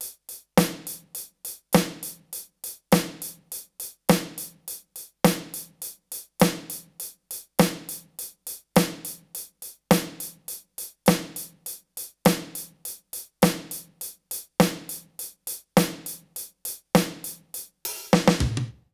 British REGGAE Loop 105BPM (NO KICK).wav